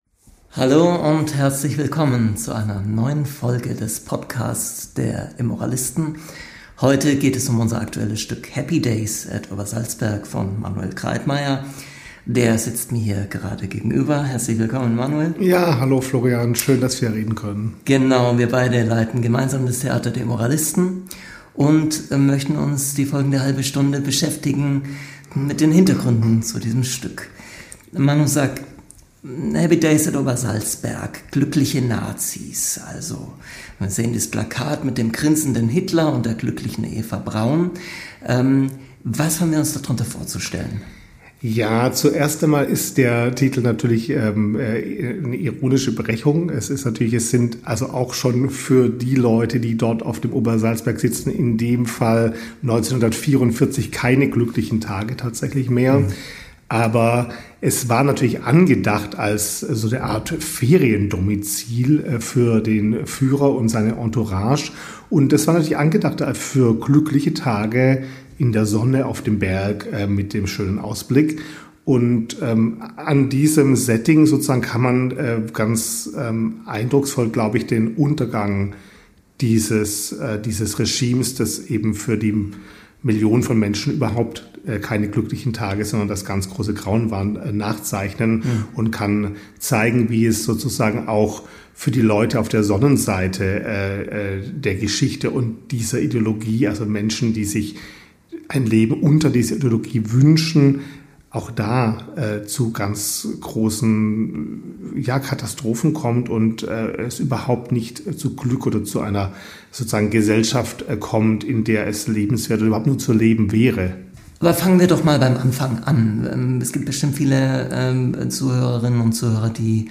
im Gespräch ~ Podcast der Immoralisten Podcast